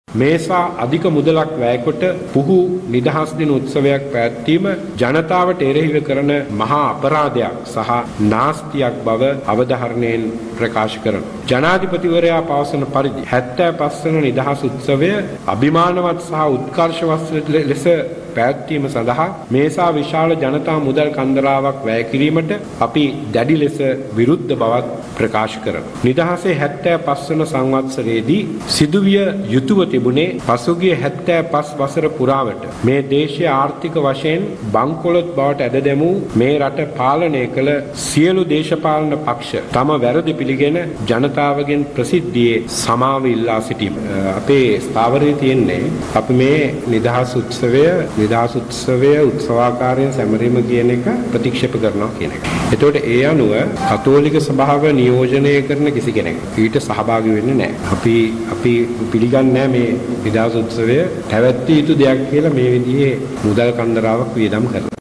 අද පැවති මාධ්‍ය හමුවකදී පැවසුවේ ආණ්ඩුව ජනතාවගේ නිදහස දැඩි ලෙස සීමා කර ඇති බවයි.